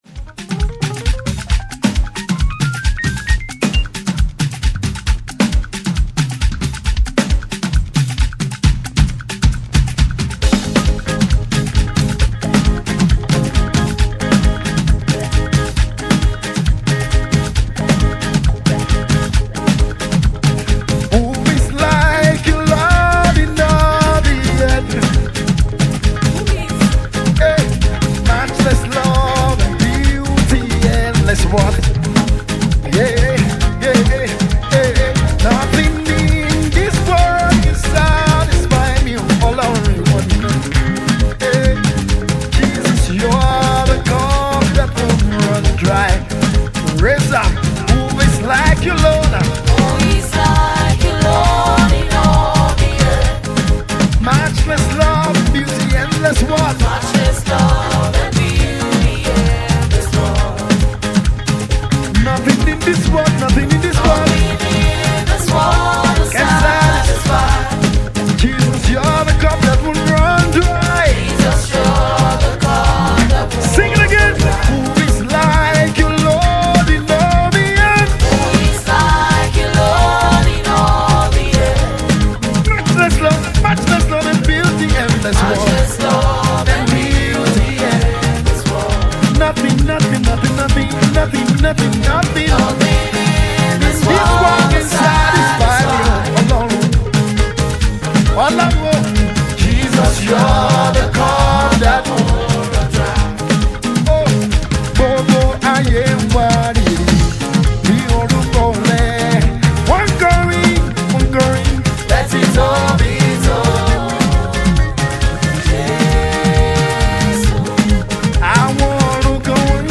uplifting melody